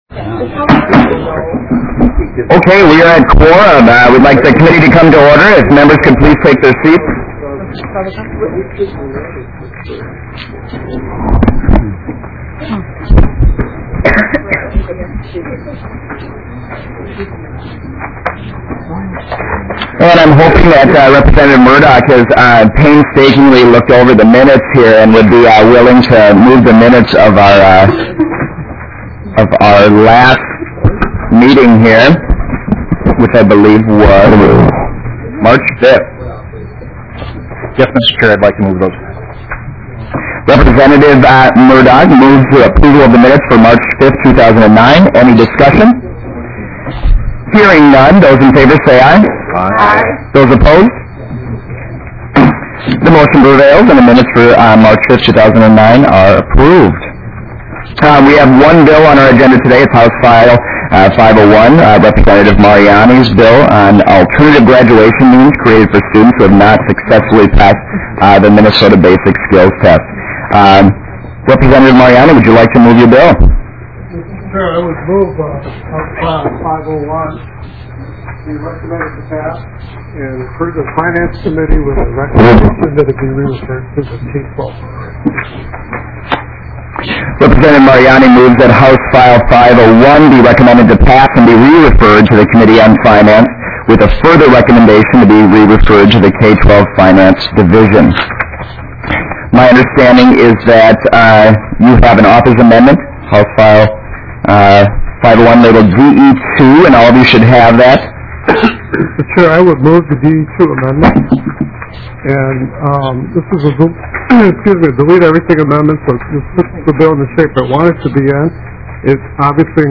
K-12 Education Policy and Oversight TWENTY-THIRD MEETING - Minnesota House of Representatives